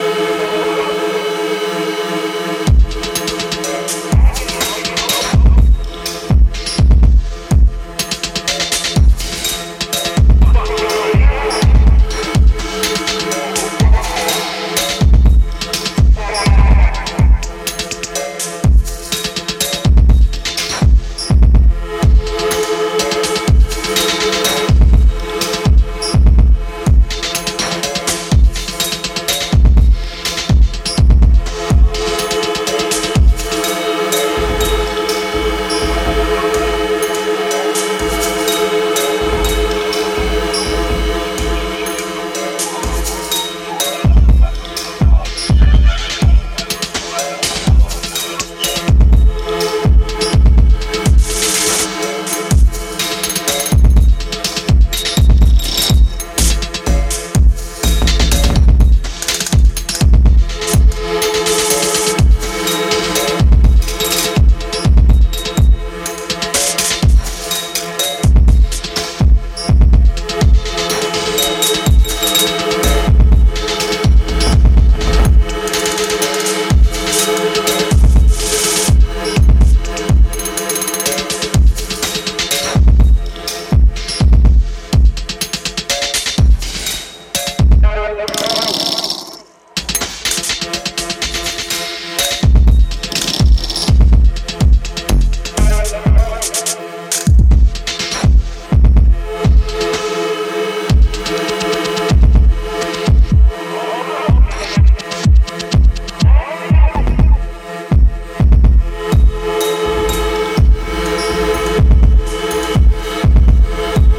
ノイジーなドローンミュージックかと思わせ、焦らされたところで現れる変則ビートに思わず頭を振ってしまう